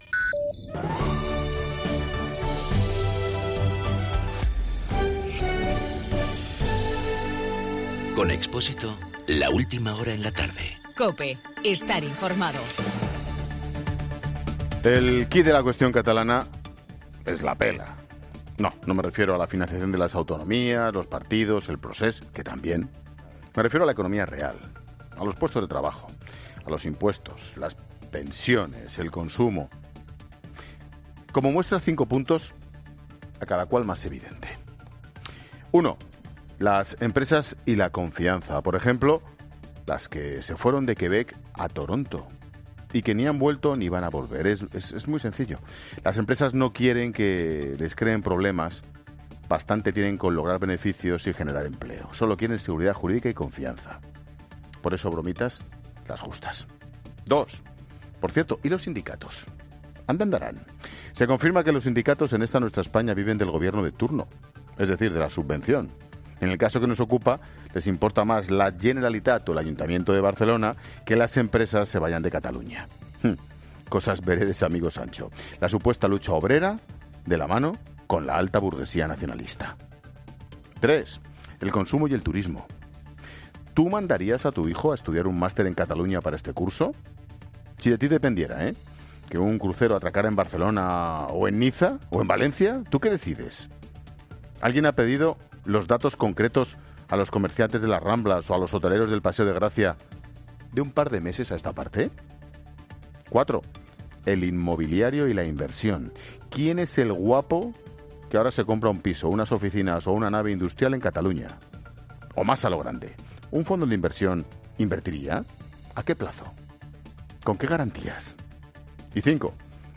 Monólogo de Expósito
El comentario de Ángel Expósito sobre lo que supondrá la independencia de Cataluña a la economía catalana.